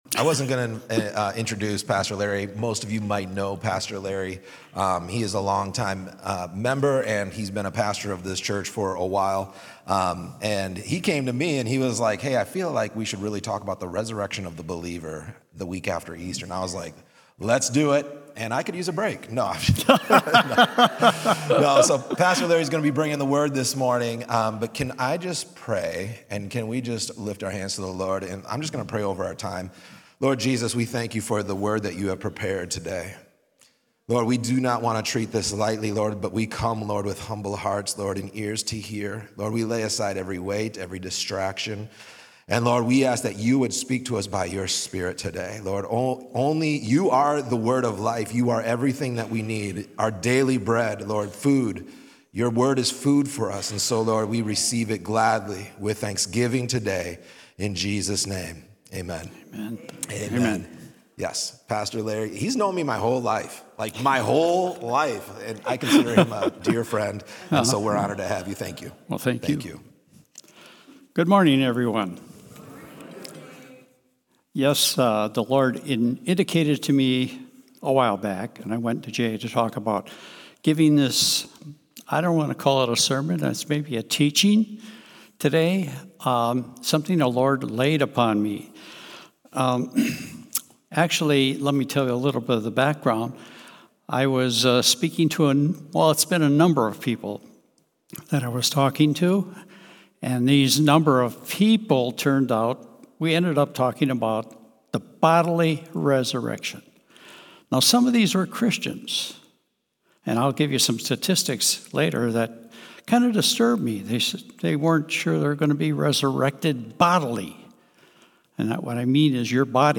Drawing from passages like John 11, 1 Corinthians 15, and Romans 8, this sermon explores the hope, power, and eternal significance of the resurrection. This teaching challenges common misconceptions, highlights the importance of resurrection in the early Church, and calls believers to reclaim this truth as a core part of their faith and testimony.